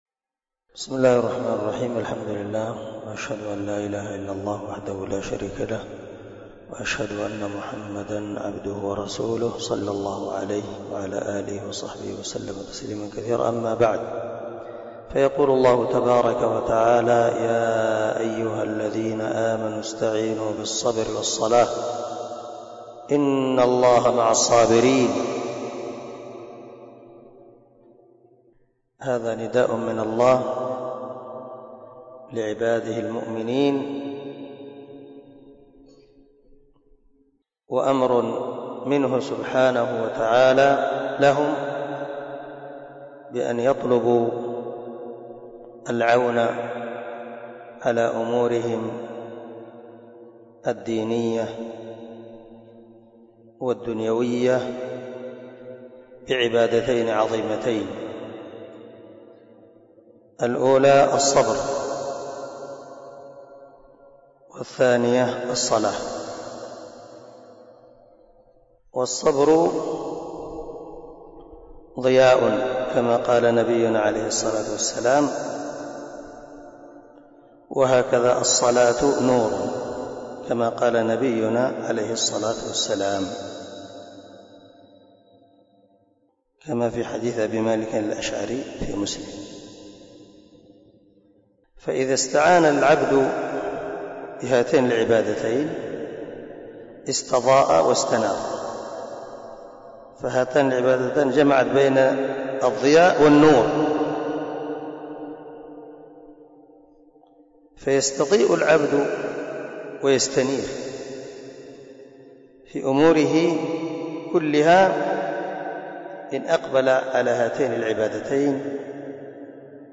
065الدرس 55 تفسير آية ( 153 ) من سورة البقرة من تفسير القران الكريم مع قراءة لتفسير السعدي